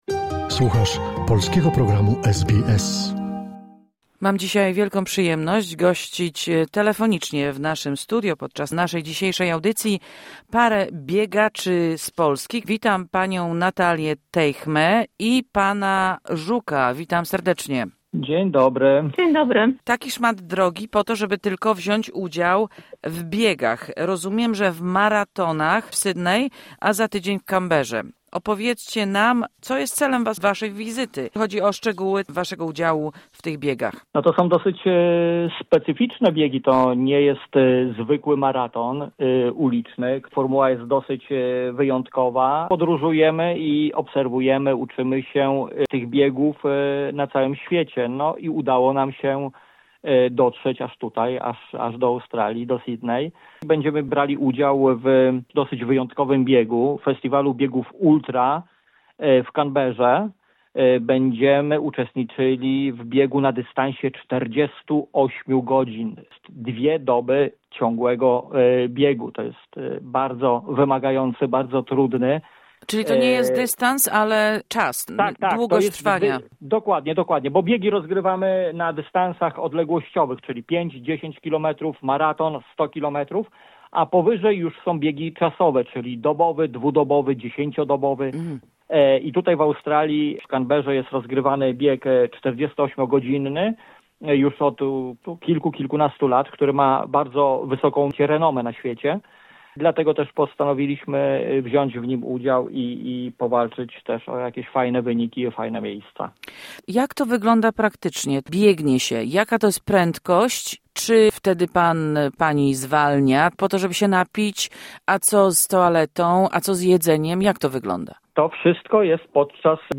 Rozmowa z polskimi maratończykami, którzy biorą udział w maratonach w Australii.